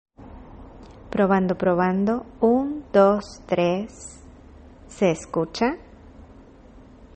• Campo de sonido: Mono
Los estuve utilizando para entrevistas en exteriores y para videos utilizando un smartphone. Aquí les puedo comparto algunas voces que hemos capturado para que vean la calidad de los micrófonos.